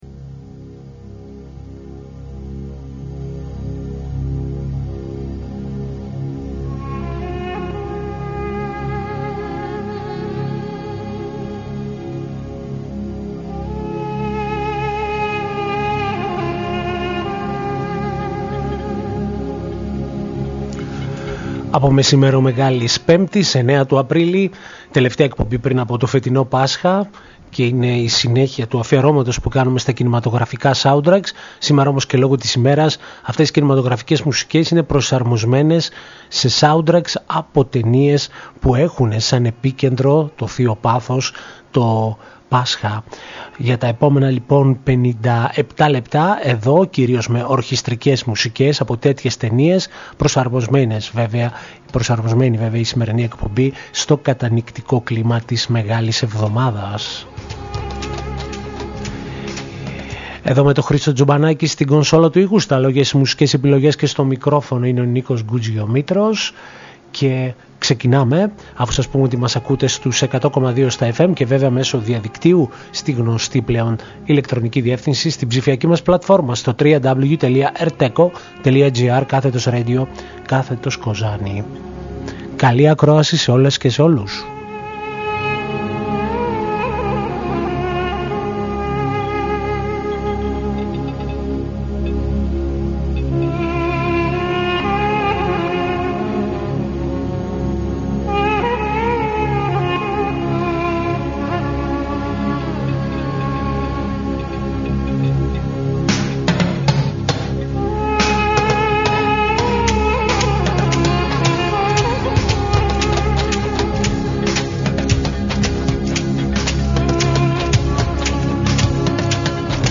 Ακολουθεί η πλήρης λίστα με τα τραγούδια της εκπομπής.